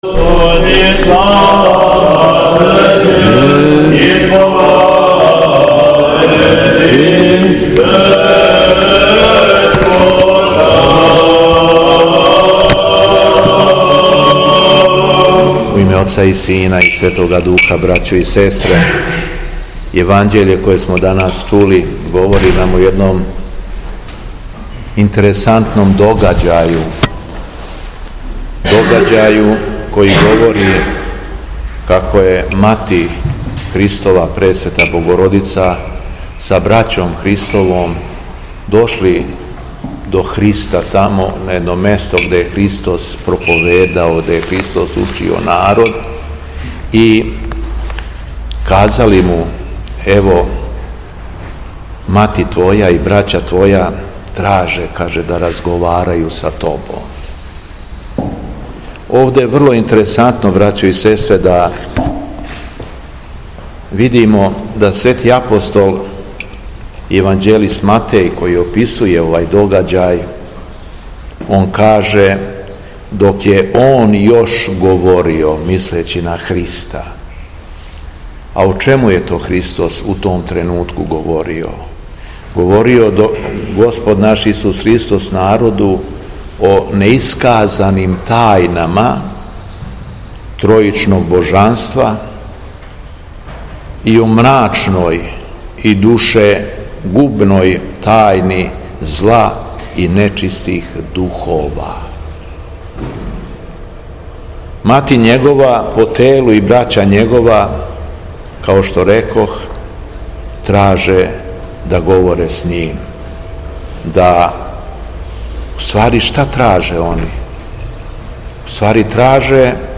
Беседа Његовог Преосвештенства Епископа шумадијског г. Јована
У пети четвртак по Духовима 06. јули 2023. године, Епископ шумадијски Г. Јован служио је свету Литургију у Старој Милошевој Цркви у Крагујевцу.